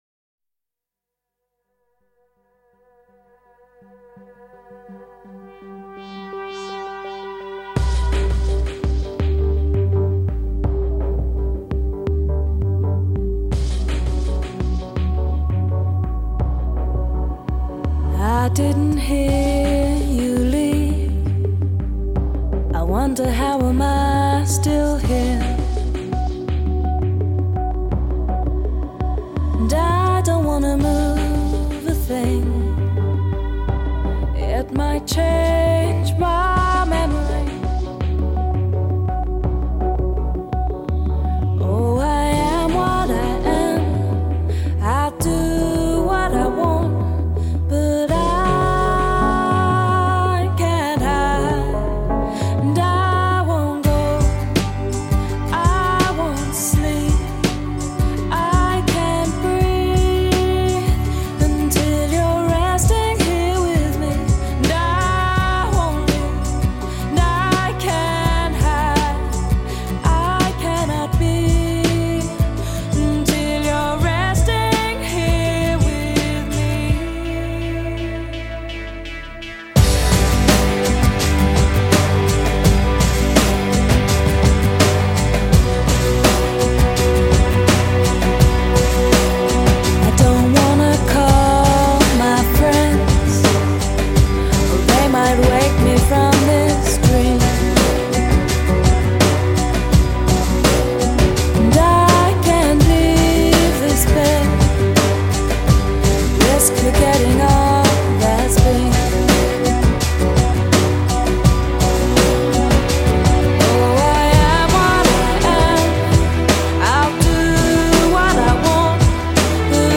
the dream pop/trip-hop arrangement